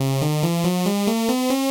描述：槽型合成器，带有法兰盘的感觉。
Tag: 140 bpm Electronic Loops Synth Loops 295.49 KB wav Key : C